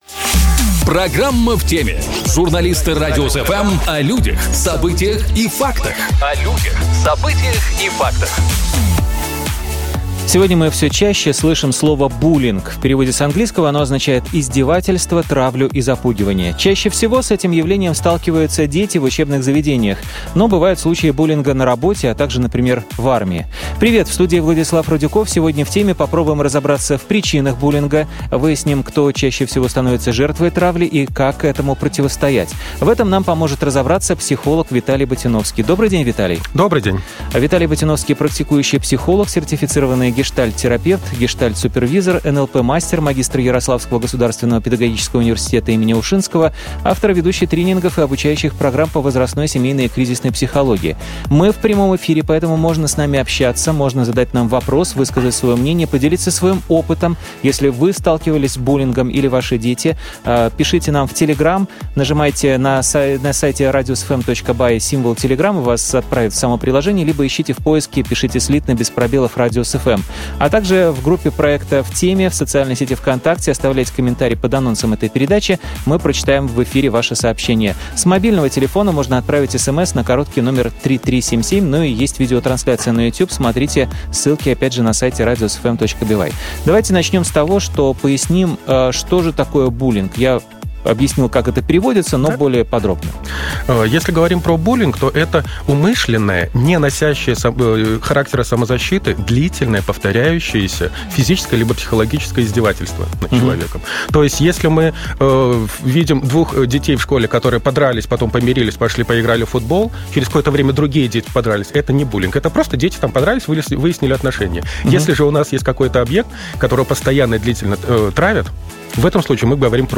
В этом нам поможет разобраться психолог